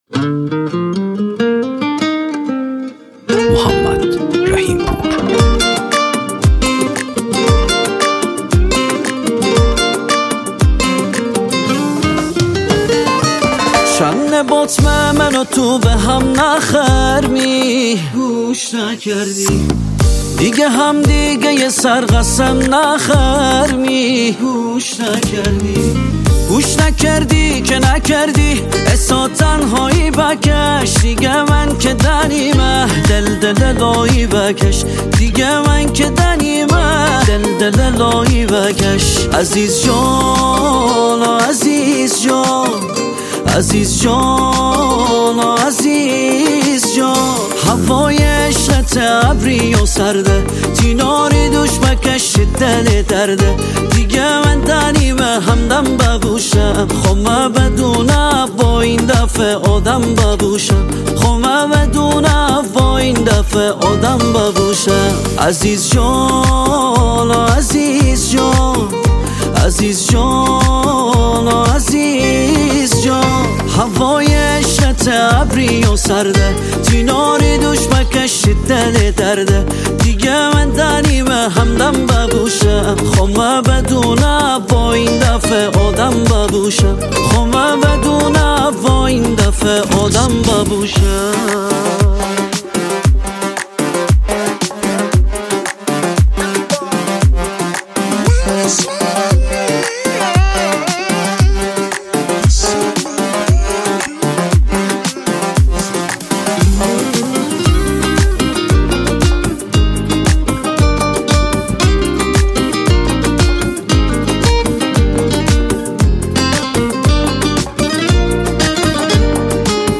آهنگ شمالی